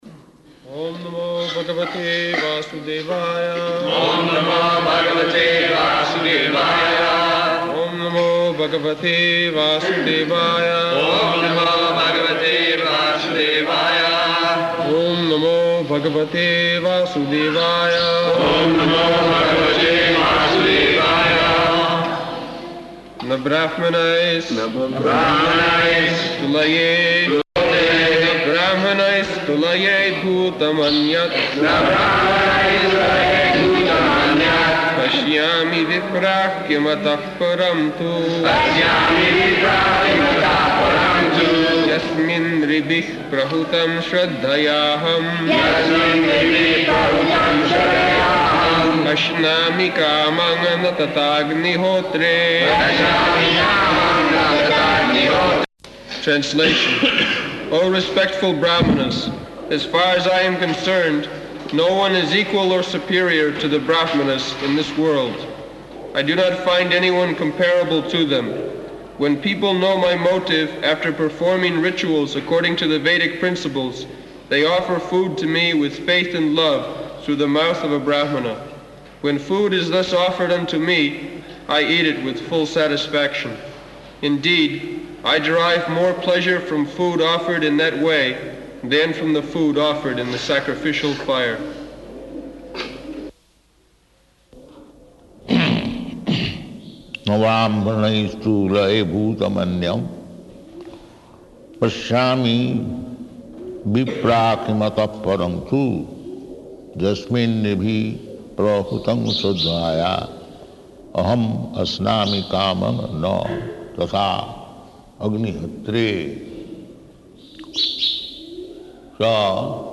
-- Type: Srimad-Bhagavatam Dated: November 10th 1976 Location: Vṛndāvana Audio file
[devotees repeat] [leads chanting of verse, etc.]